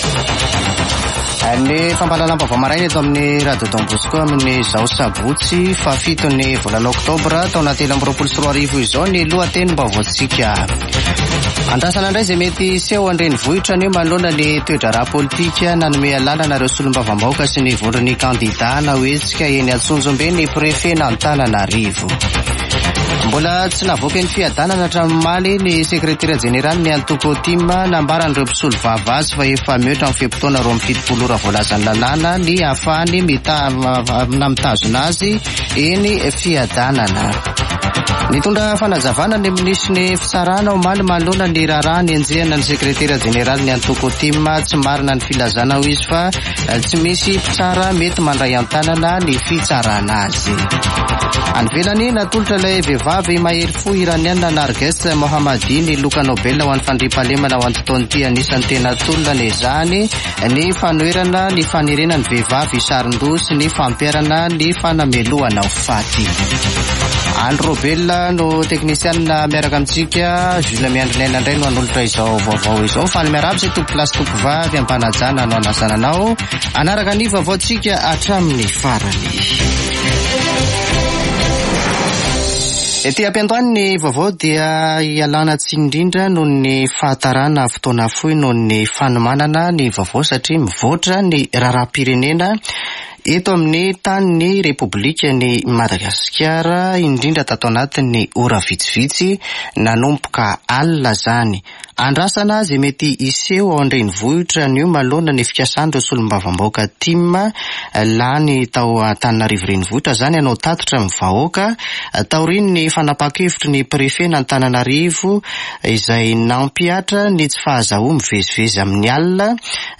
[Vaovao maraina] Sabotsy 7 ôktôbra 2023